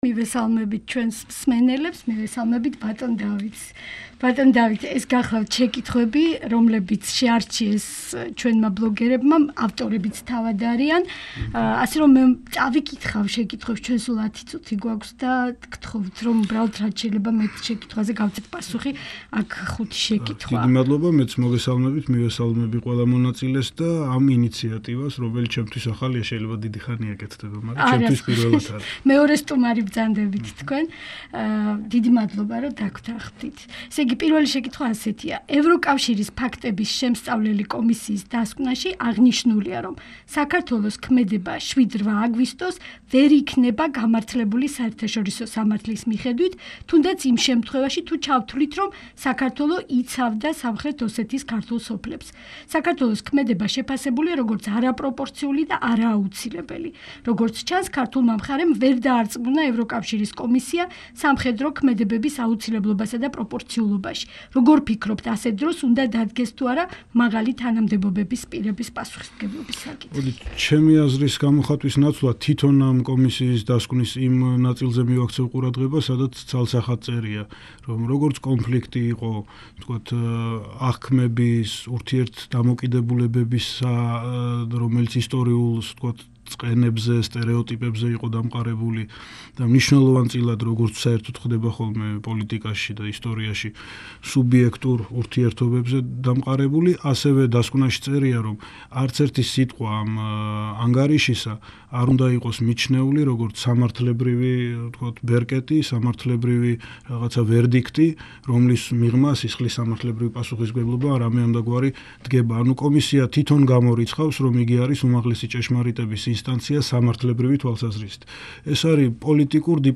ინტერვიუ დავით დარჩიაშვილთან
რადიო თავისუფლება კვირაში ერთხელ, ორშაბათობით, საღამოს მთავარ გადაცემაში უთმობს ეთერს ბლოგების მომხმარებელთა მიერ შერჩეულ სტუმარს. დღეს გადაცემის სტუმარია საქართველოს პარლამენტის წევრი დავით დარჩიაშვილი. მან პირდაპირ ეთერში უპასუხაში ბლოგების მომხმარებელთა შეკითხვებს. დღესვე, 20.00–დან, „რადიო თავისულების“ ბლოგს ეწვევა და ინტერნეტის მეშვეობით გაესაუბრება მსურველებს.